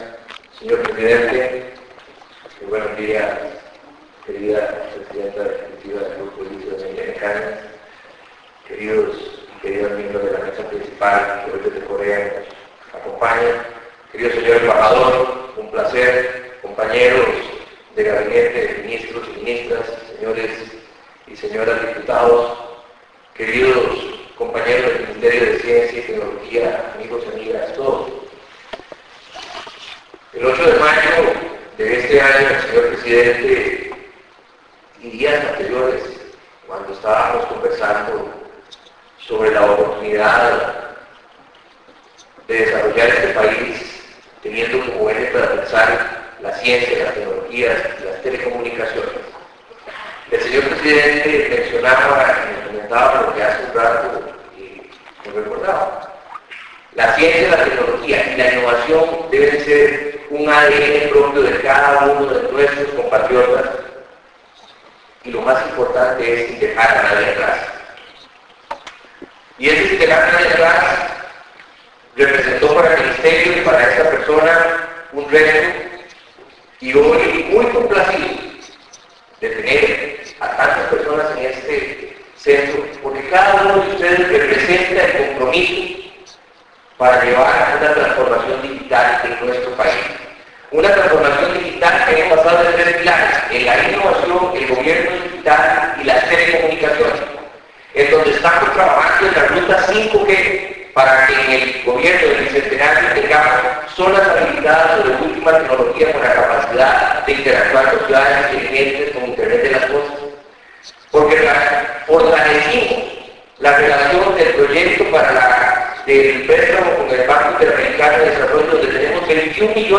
Discurso del ministro Luis Adrián Salazar en inauguración del Sistema de Información Nacional de Ciencia y Tecnología (SINCYT)